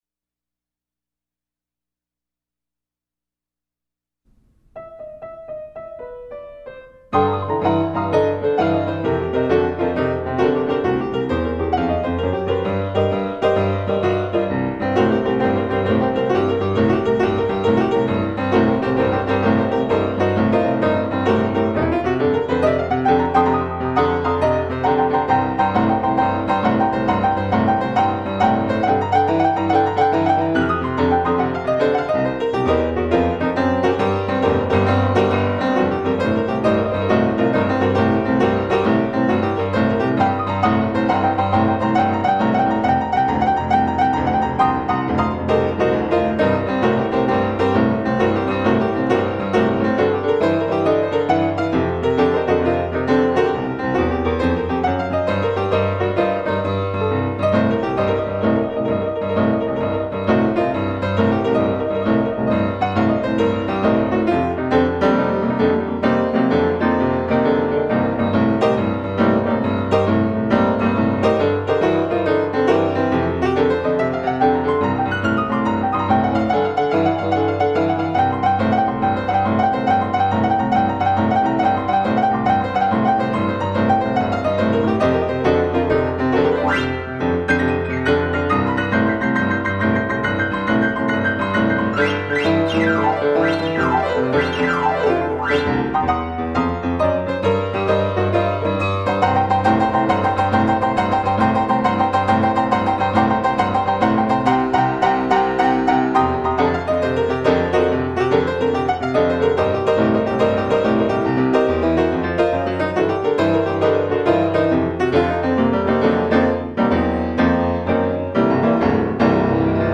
Gospel Rockin' Boogie & Good News Blues in the Key of 'K'